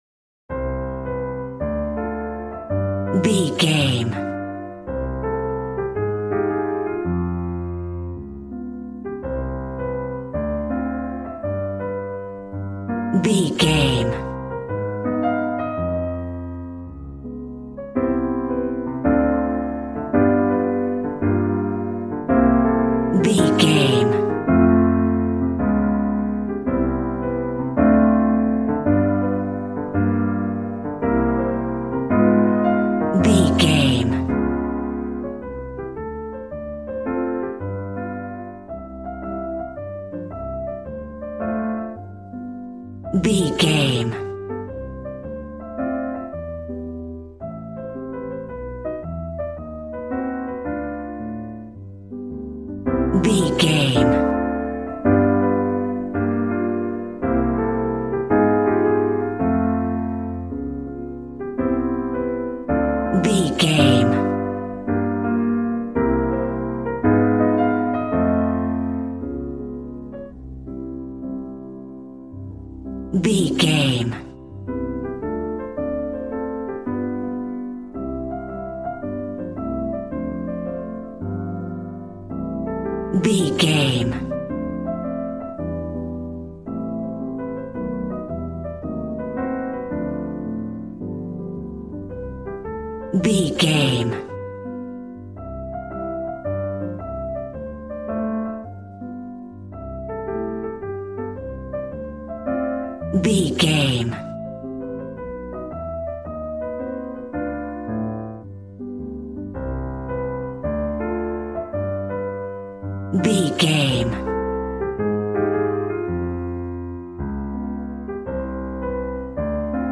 Smooth jazz piano mixed with jazz bass and cool jazz drums.,
Ionian/Major
piano